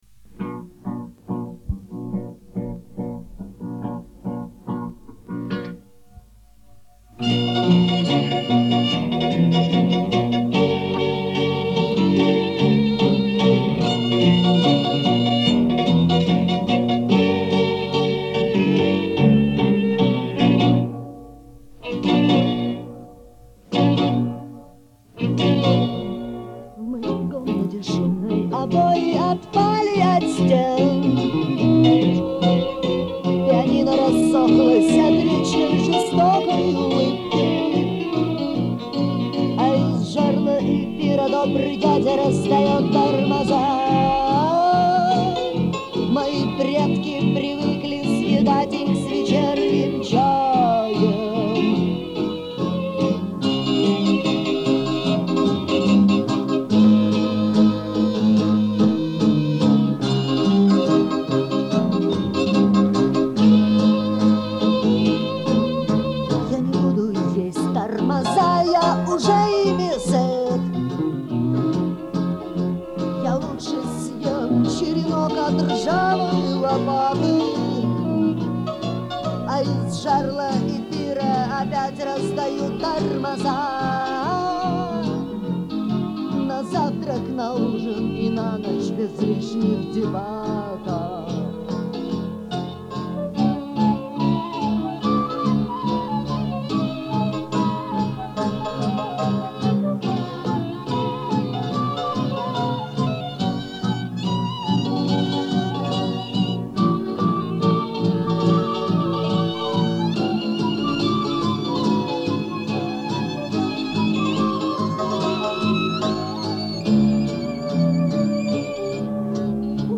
Барнаульская рок-группа
Первая и лучшая запись на магнитофонной кассете.